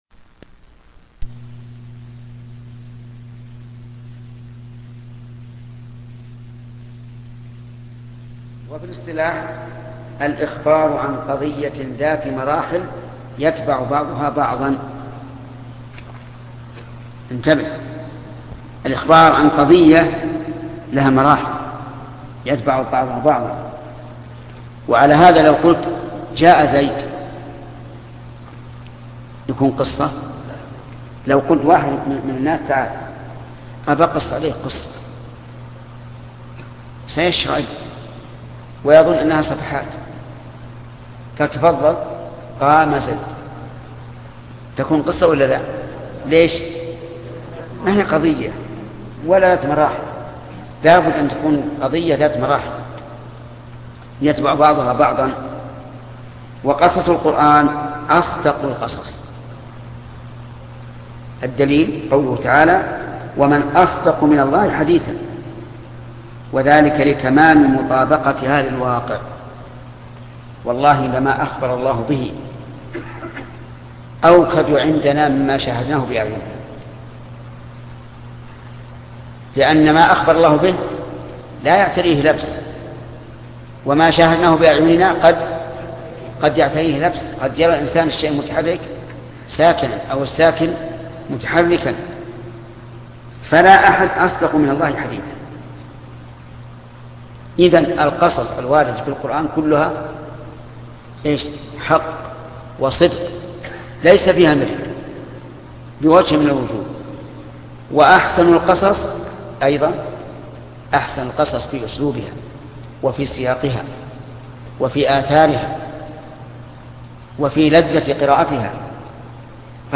شرح أصول في التفسير الدرس 12